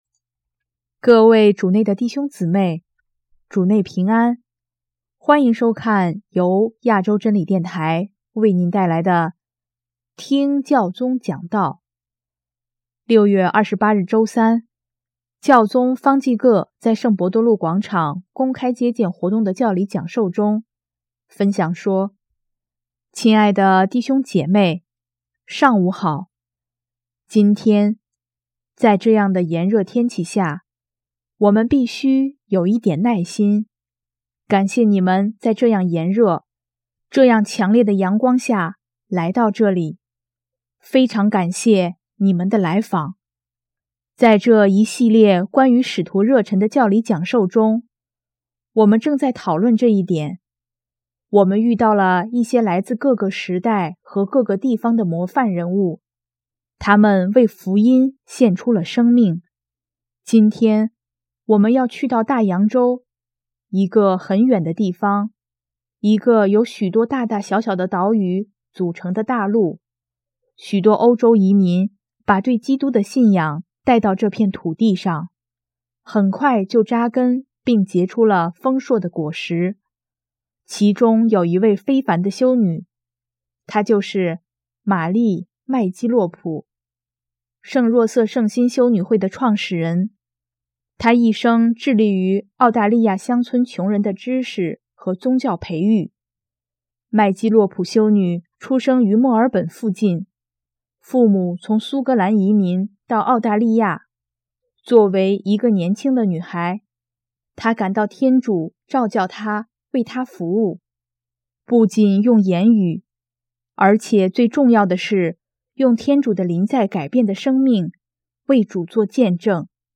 【听教宗讲道】|用改变的生命为主作见证
6月28日周三，教宗方济各在圣伯多禄广场公开接见活动的教理讲授中，分享说：